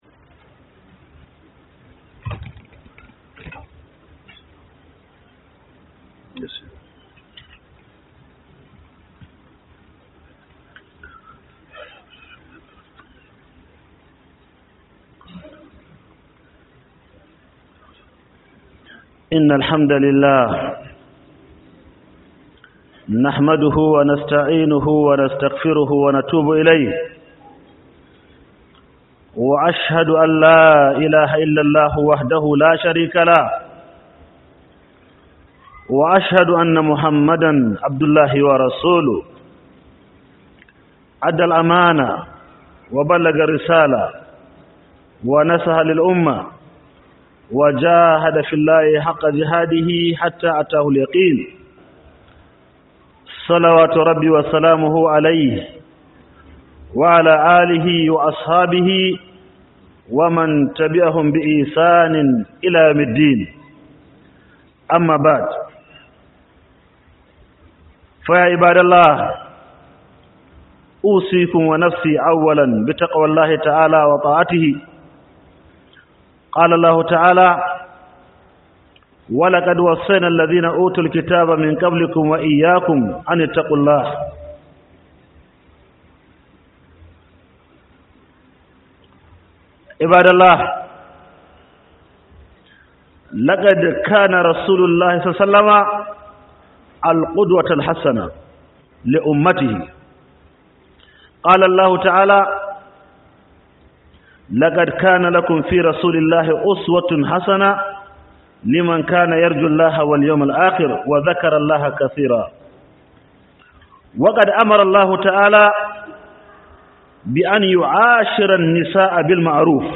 KYAUTATA MA MATA - HUDUBA